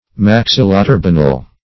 Search Result for " maxilloturbinal" : The Collaborative International Dictionary of English v.0.48: Maxilloturbinal \Max*il`lo*tur`bi*nal\, a. [Maxilla + turbinal.]